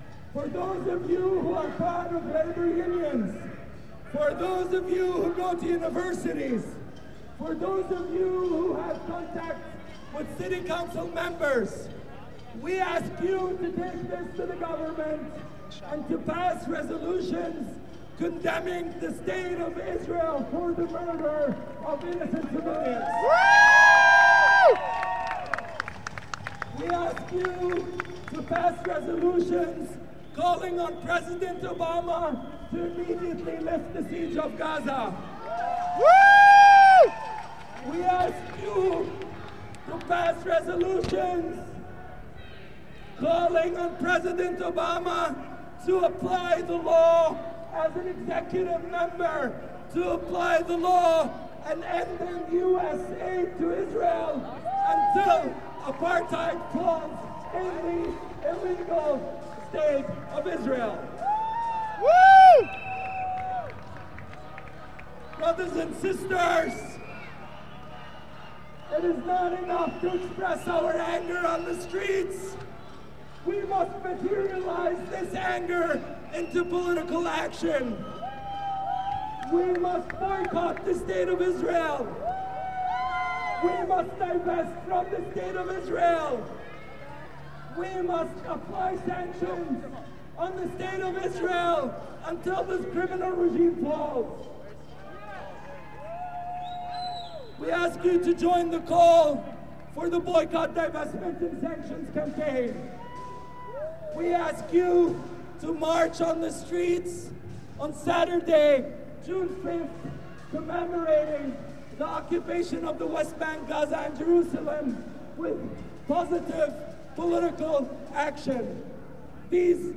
Two speech excerpts and three interviews from the 6/4/10 protest at the Israeli consulate
Unfortunately the jostling made it impossible to get good sound quality for the "bullhorn" speakers (and, in one case, even to get his name).
§Speaker: Unidentified